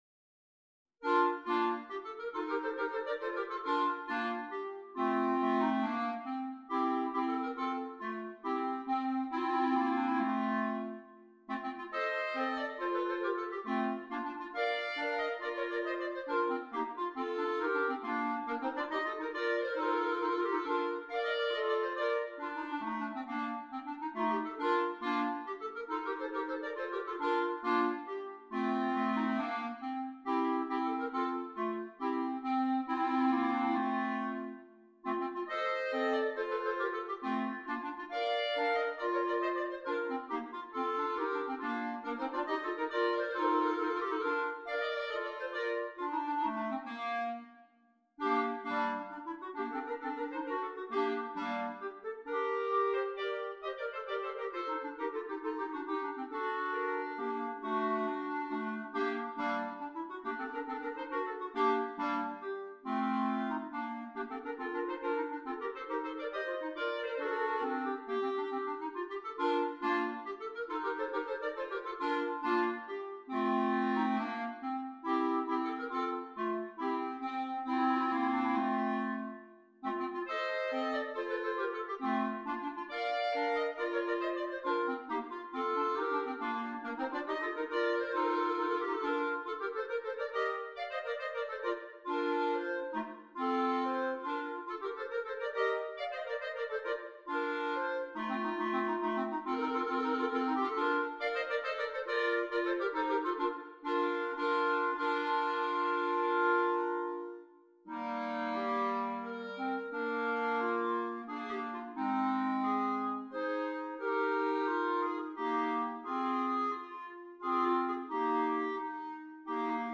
3 Clarinets